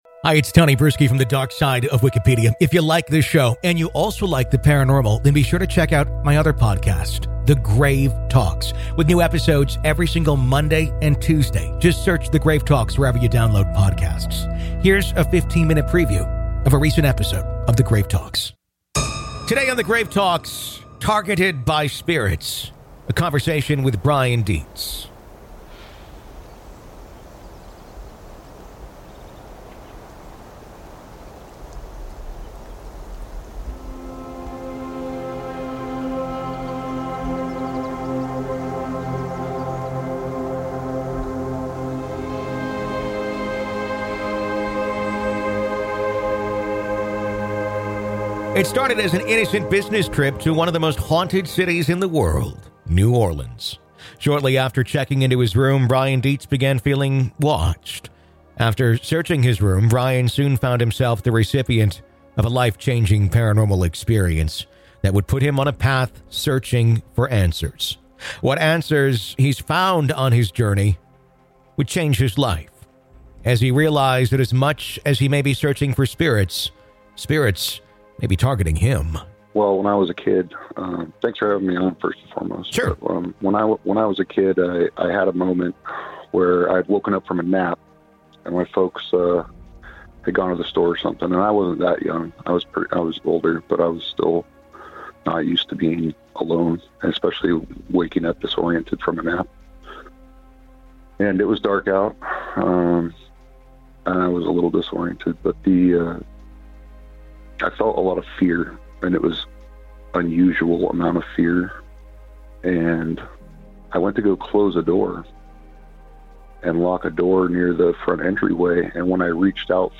Targeted By Spirits | A Conversation